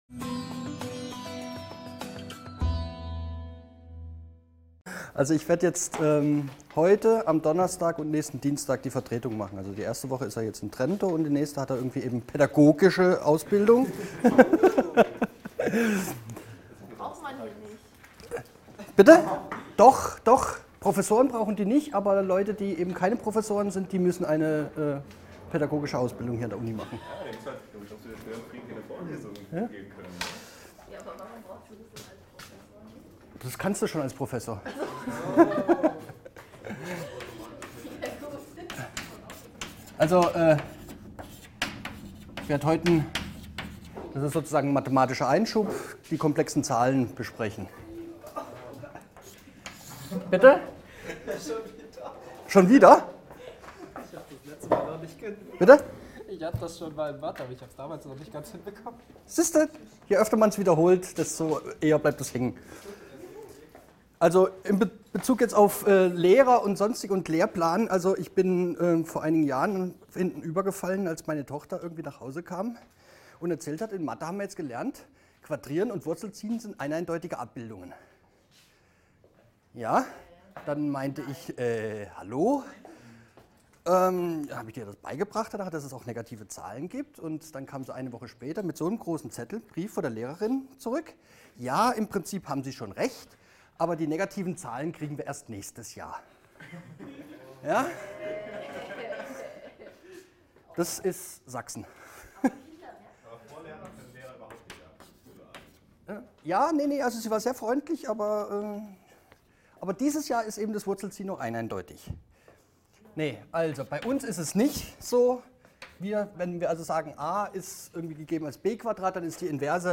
Aufgrund eines Kabelbruchs ist der Ton leider nicht vorhanden.